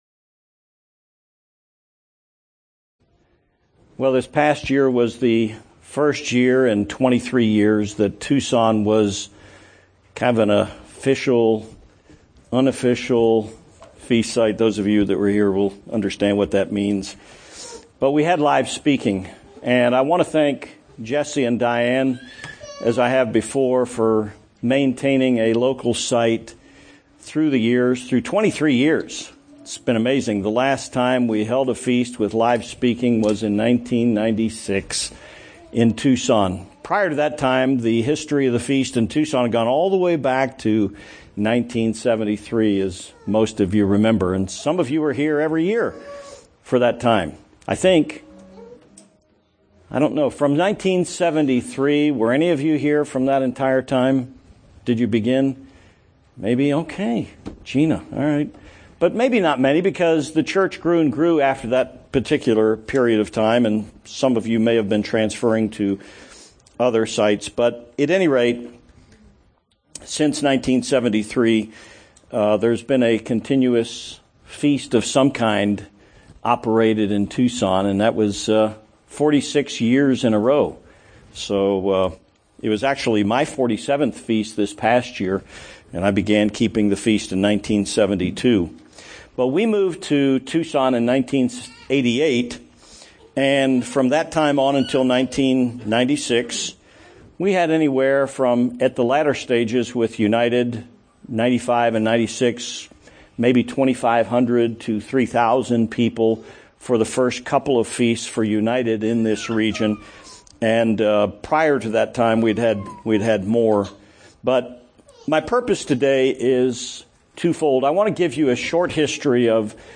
This sermon traces the history of keeping the Feast of Tabernacles in Tucson, and reviews the history of Feast-keeping in the Bible through the last 2,000 years.
Given in Tucson, AZ